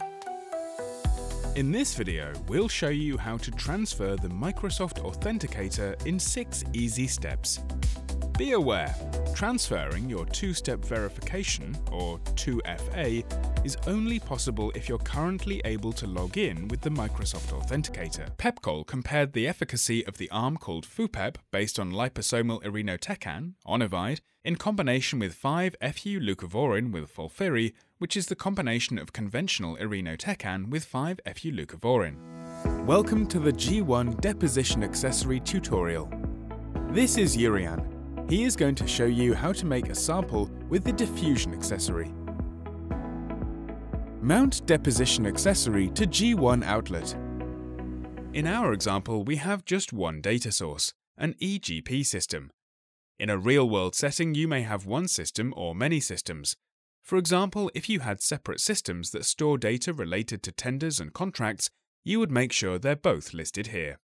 Inglés (Británico)
Natural, Seguro, Cálida, Amable, Empresarial
E-learning